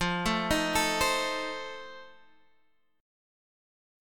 Listen to F7 strummed